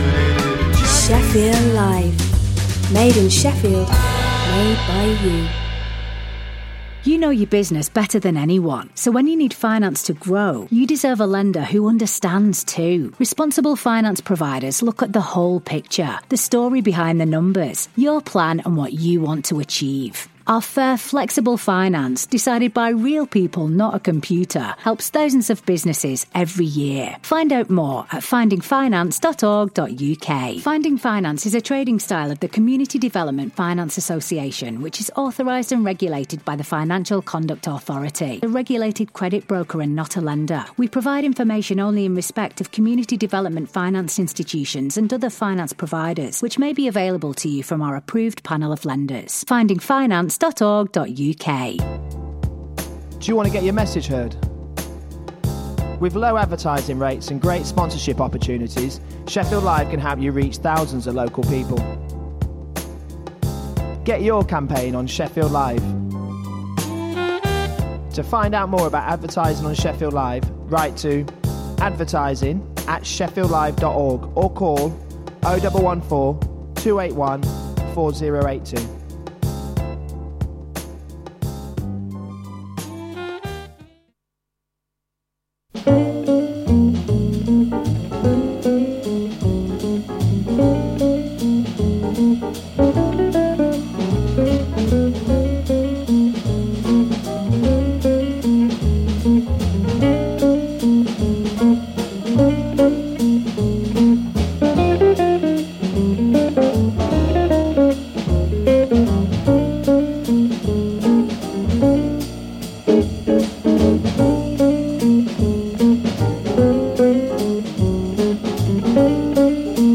Film and theatre reviews plus swing classics.